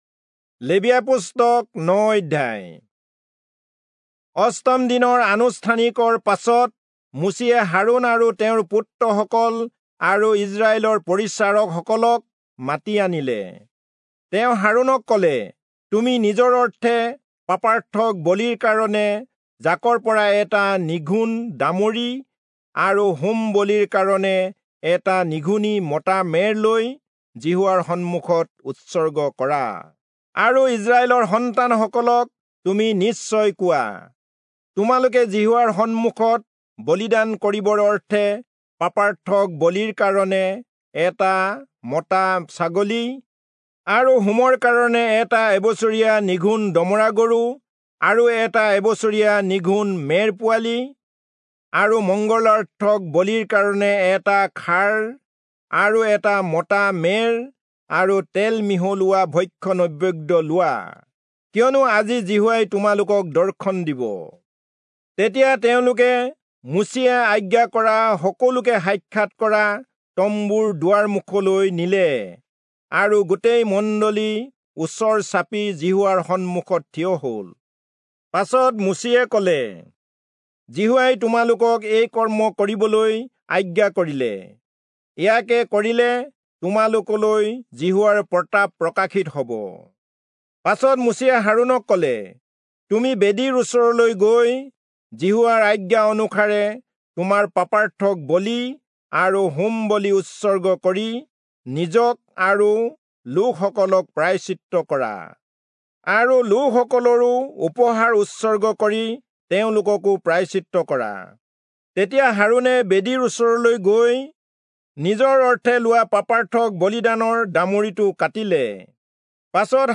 Assamese Audio Bible - Leviticus 9 in Tov bible version